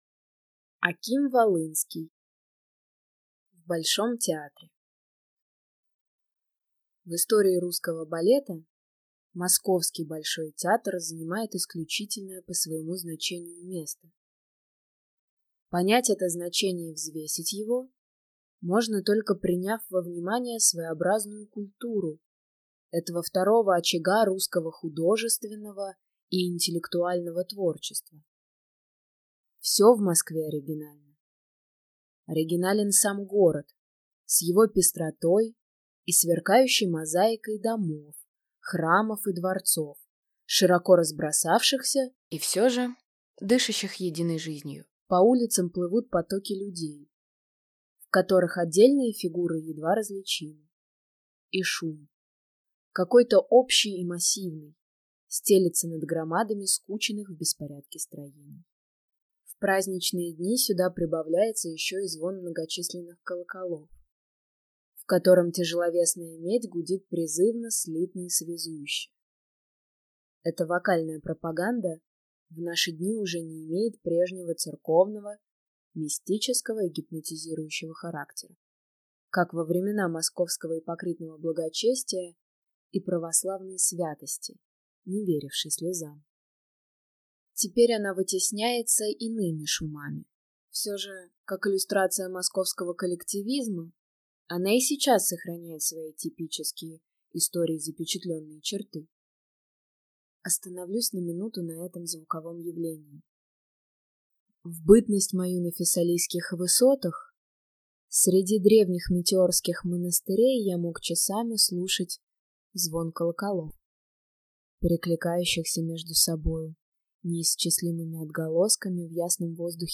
Аудиокнига В Большом театре | Библиотека аудиокниг